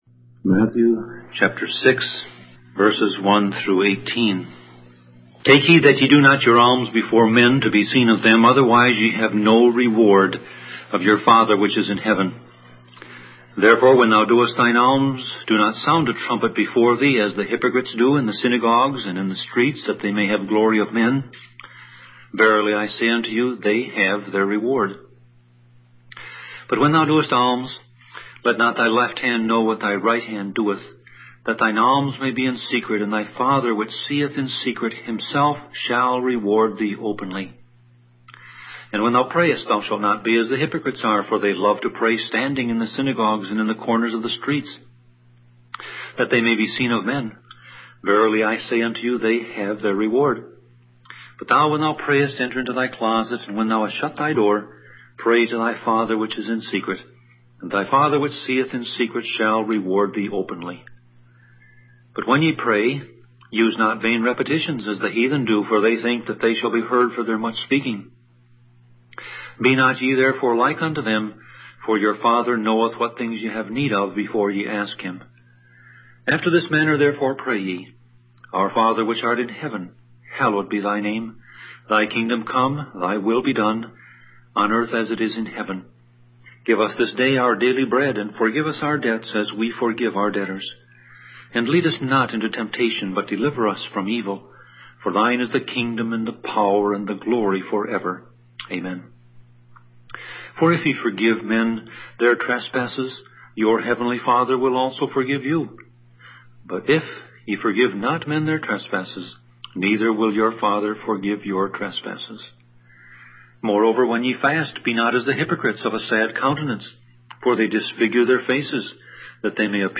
Sermon Audio Passage: Matthew 6:1-18 Service Type